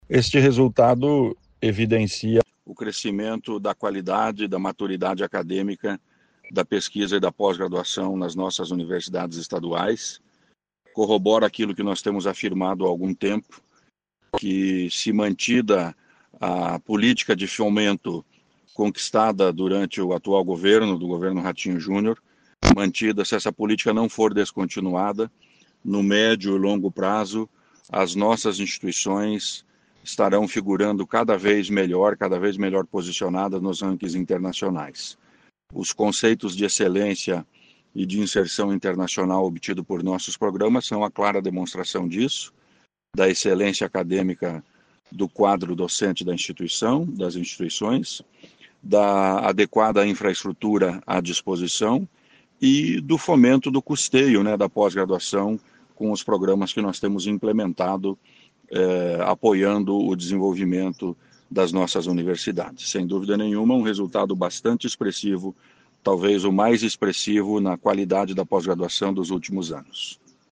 Sonora do secretário Estadual da Ciência, Tecnologia e Ensino Superior, Aldo Bona, sobre o aumento de programas bem avaliados pela Capes nas universidades estaduais